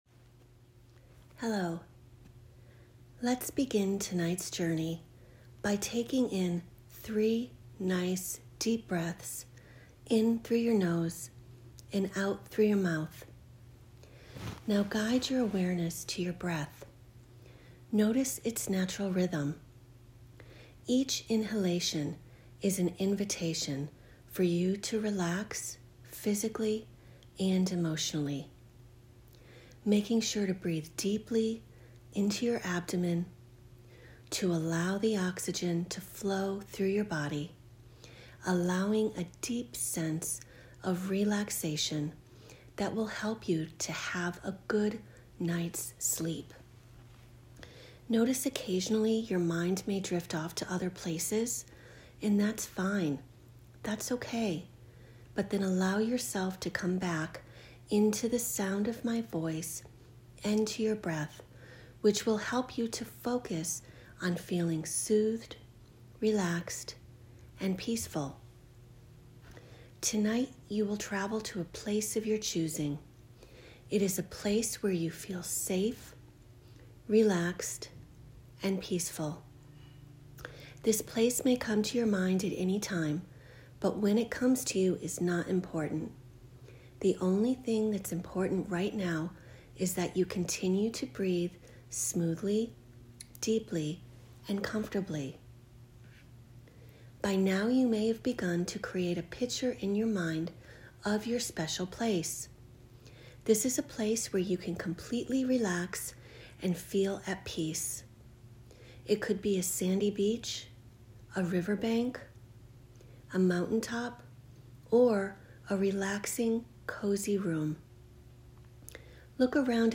Audio Recording: Visualization Recording for Sleep –